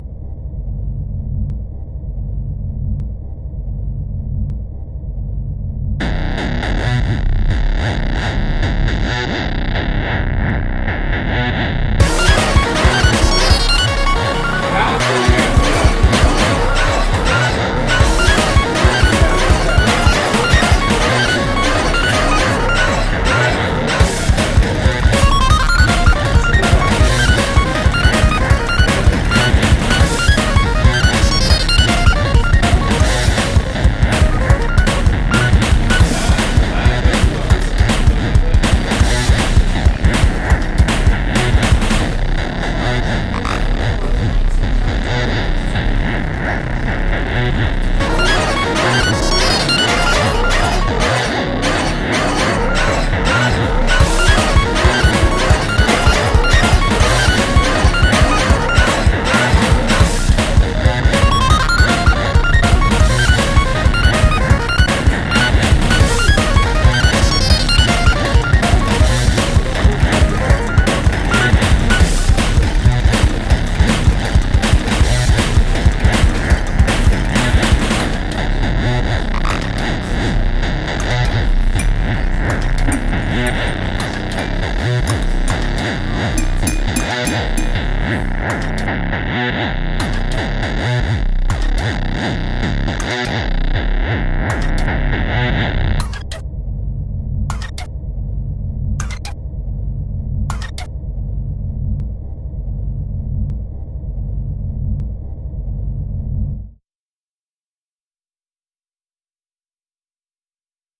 Breakfast never sounded so techno.